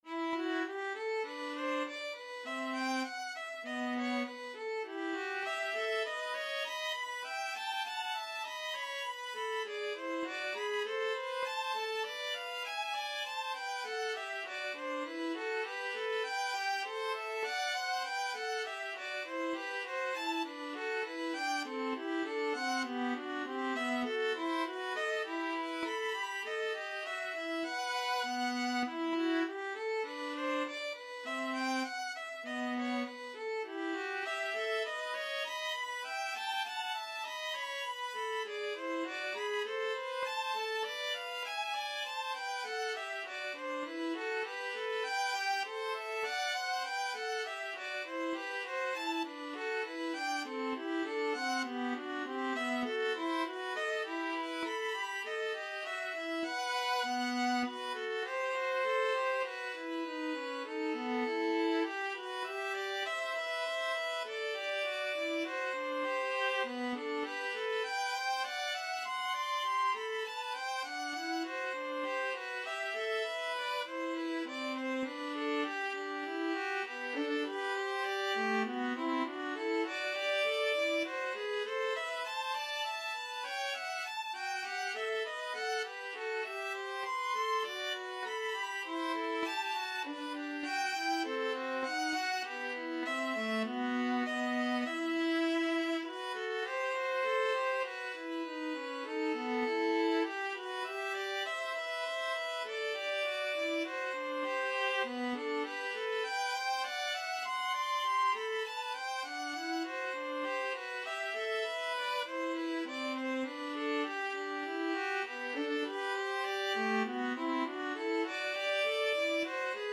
2/2 (View more 2/2 Music)
Classical (View more Classical Violin-Viola Duet Music)